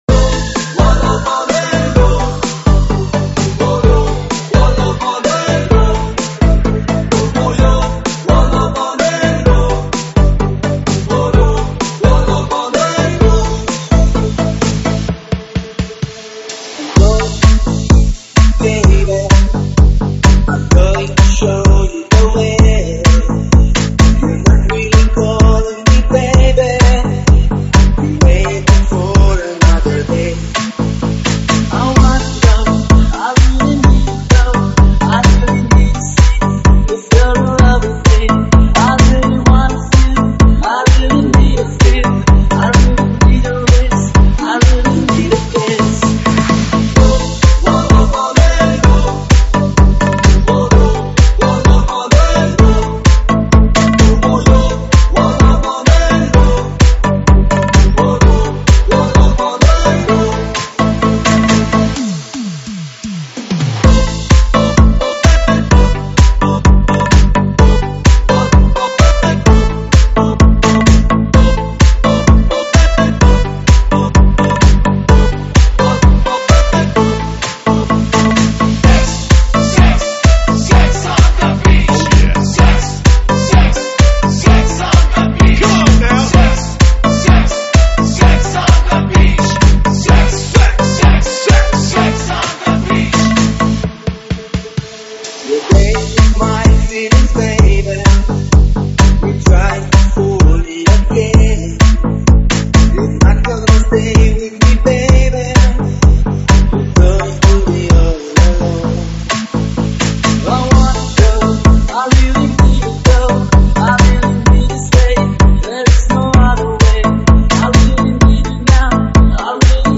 ProgHouse